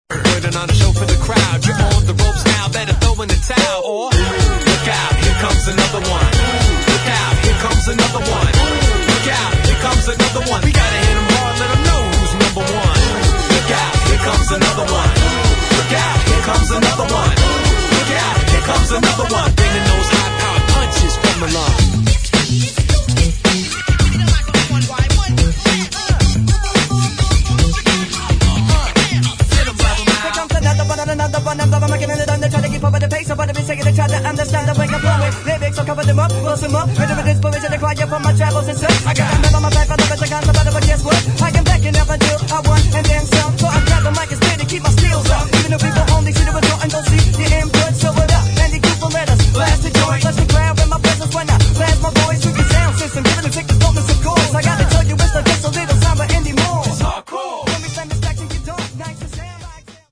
[ HIP HOP ]